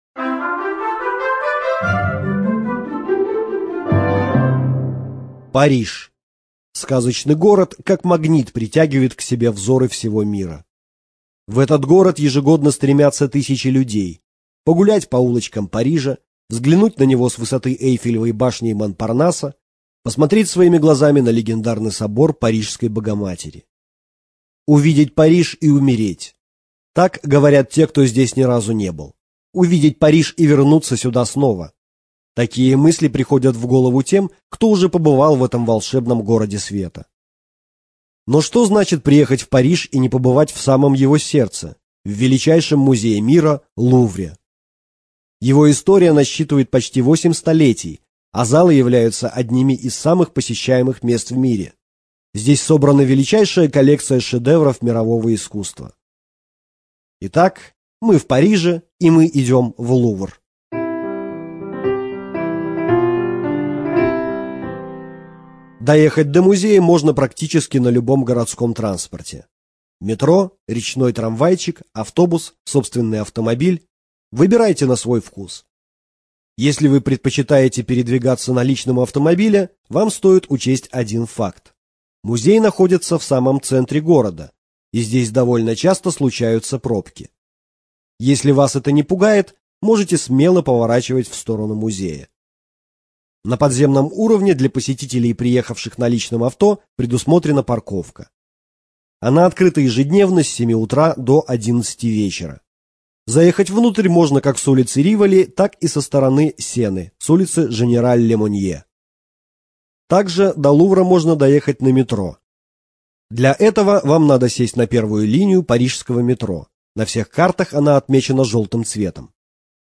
ЖанрАудиоэкскурсии и краеведение